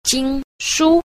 1. 經書 – jīngshū – kinh thư (kinh sách)
jing_shu.mp3